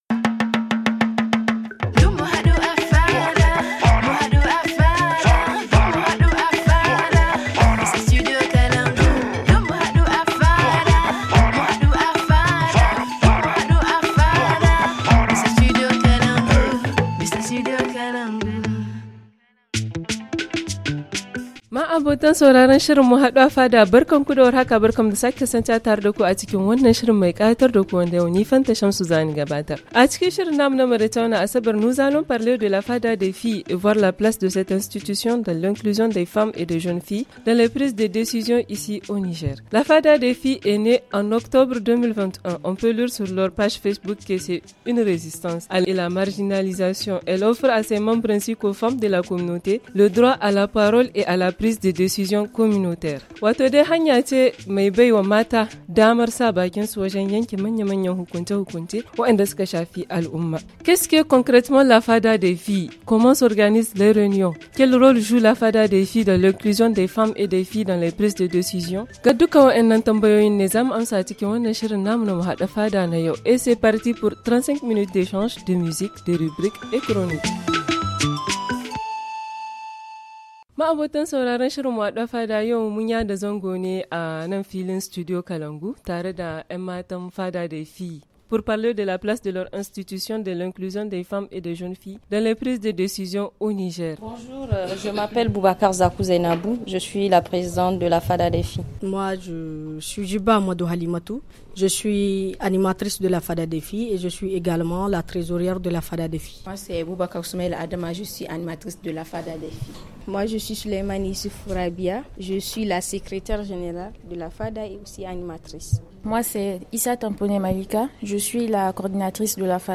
Cette semaine la fada du studio est en compagnie de la “fada des filles”.